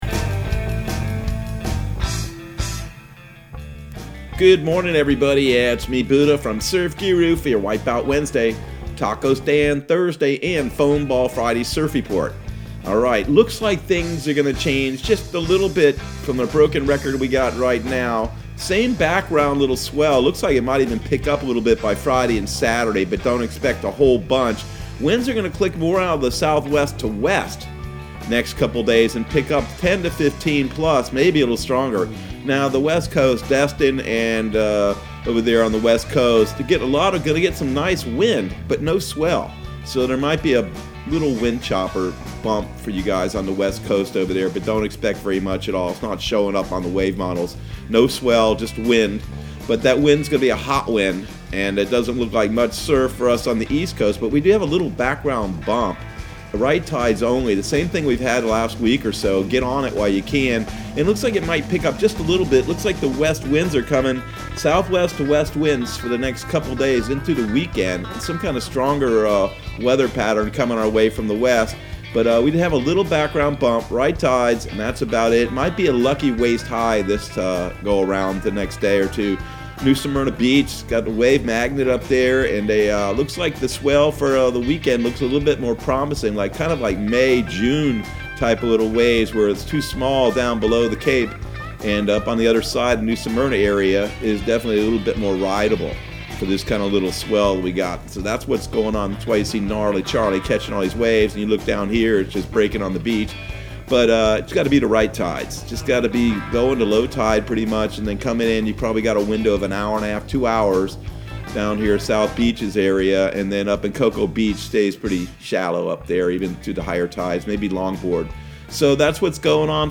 Surf Guru Surf Report and Forecast 07/08/2020 Audio surf report and surf forecast on July 08 for Central Florida and the Southeast.